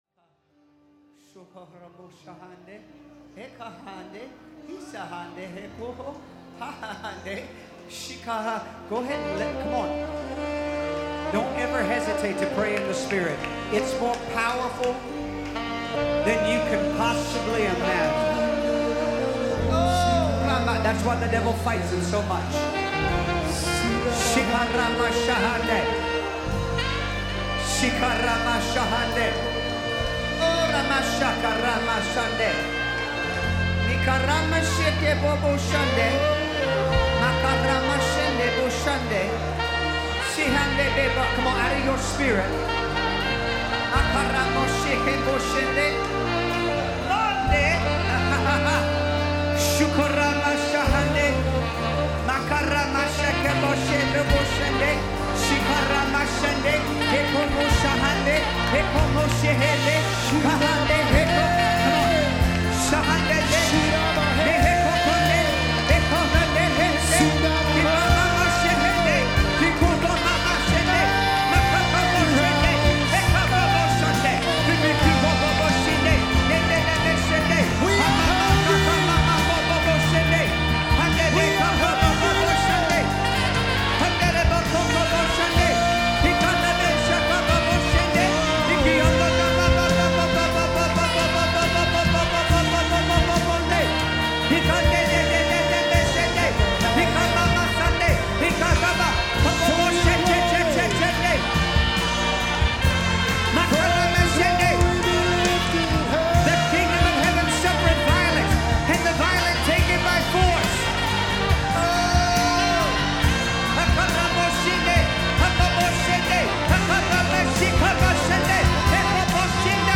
Worship Samples